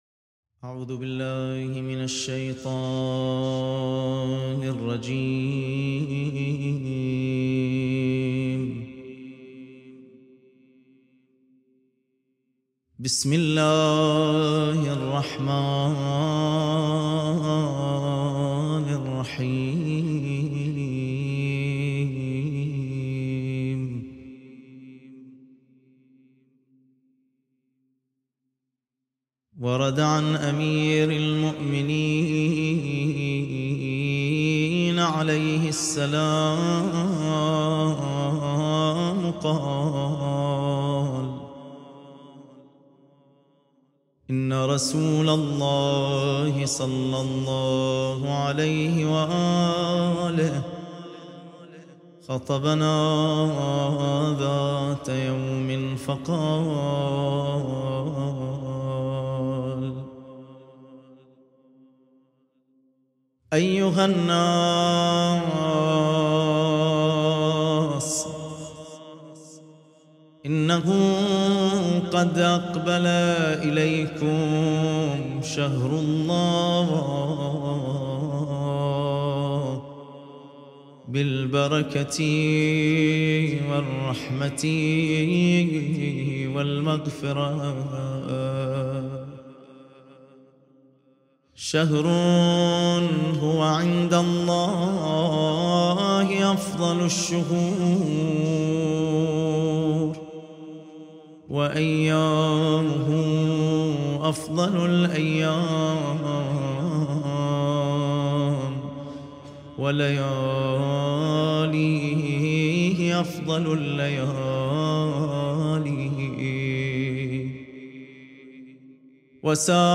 خطبة النبي (ص) في استقبال شهر رمضان